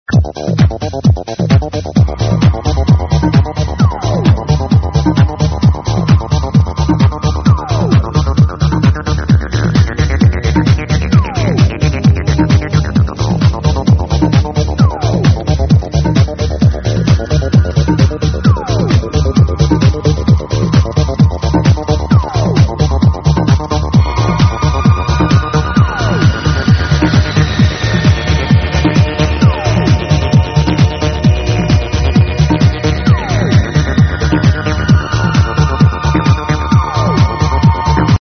old soft techno track from 1994
it's an old file from 1994 or earlier, something like tripping soft techno.